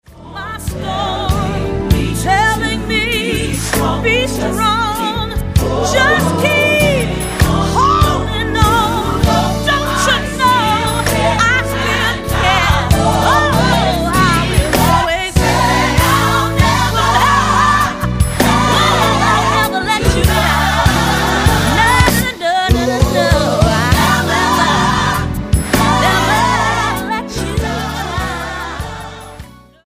STYLE: Gospel
featuring some impressive vocal acrobatics
traditional gospel stylings